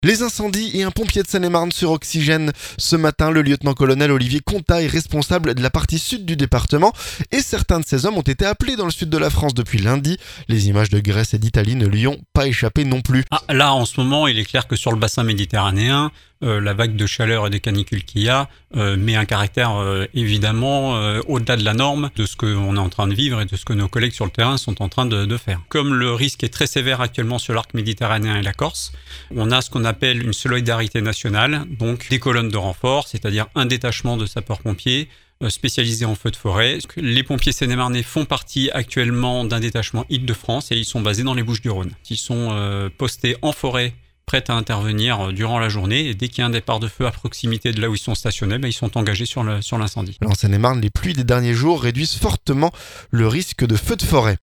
Incendies : un pompier de Seine-et-Marne sur Oxygène ce jeudi.